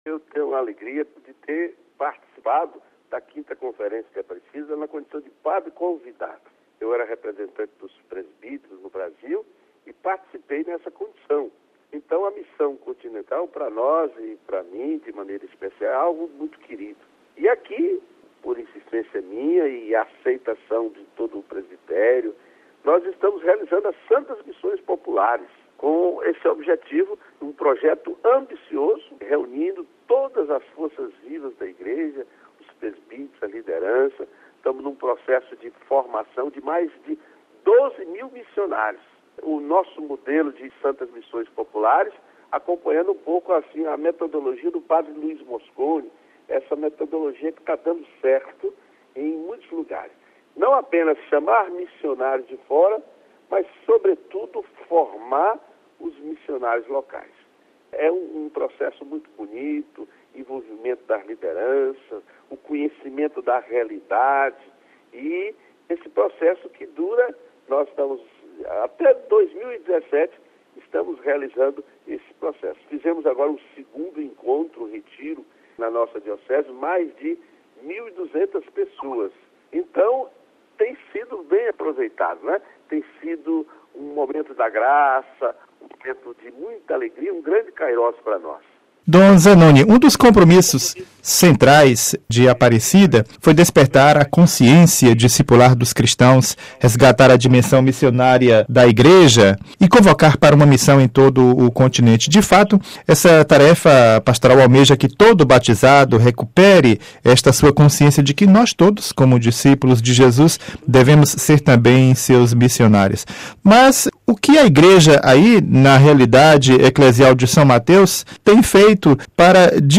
Para nos falar sobre esta realidade, trazemos as considerações e apreciações de Dom Zanoni Demettino Castro, deste dezembro de 2007 à frente desta Igreja particular do Estado do Espírito Santo.